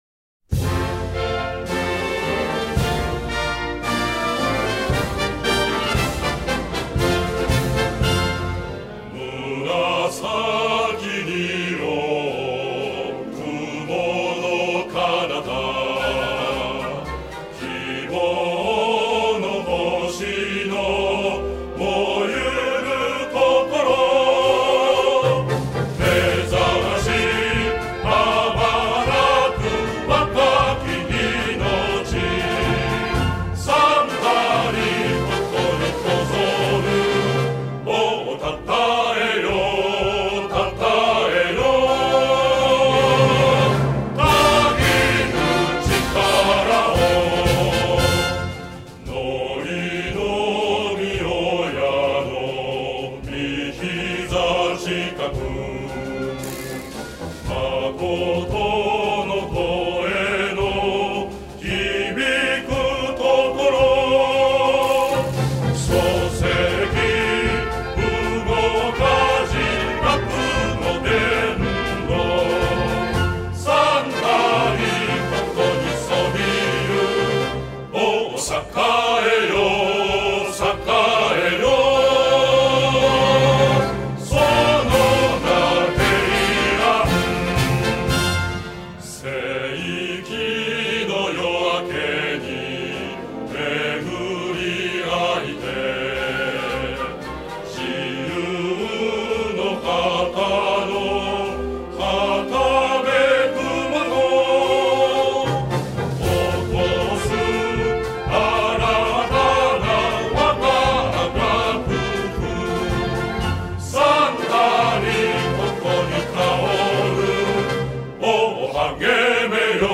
校歌 | 龍谷大学付属 平安高等学校・中学校